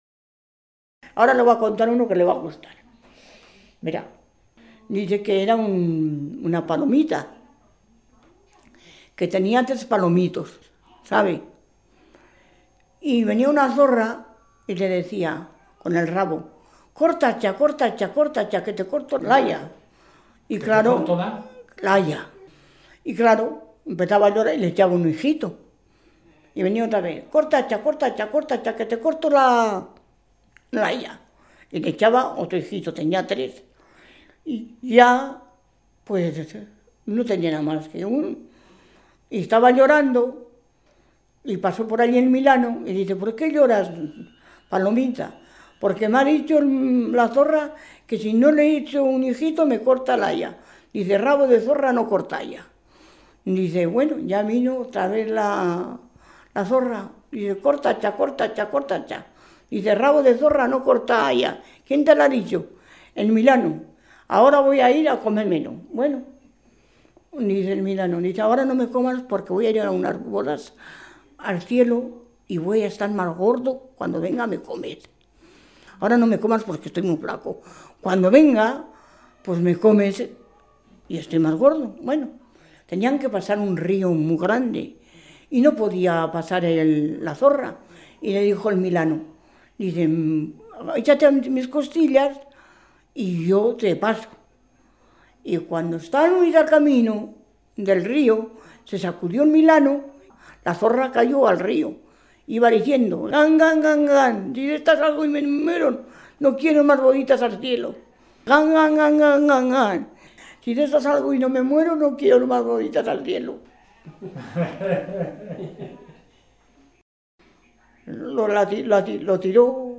Clasificación: Cuentos
Lugar y fecha de recogida: Villoslada de Cameros, 8 de diciembre de 2000